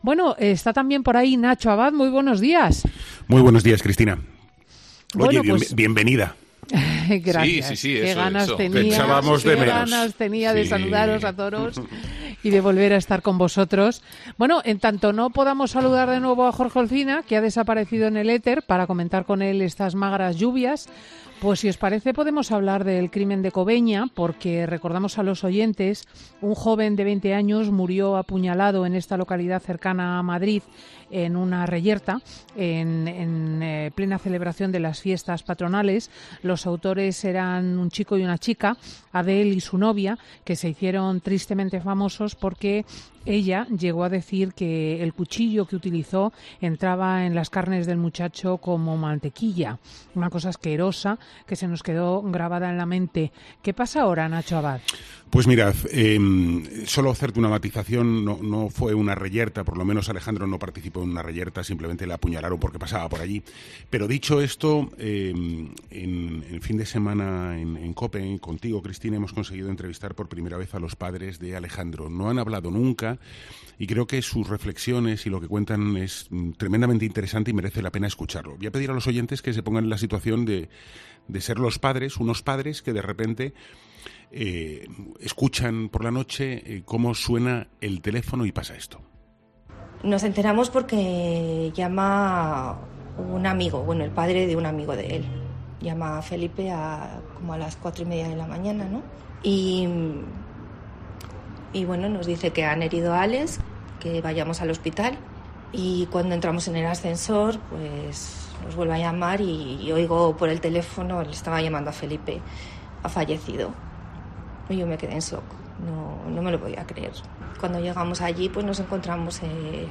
en una entrevista exclusiva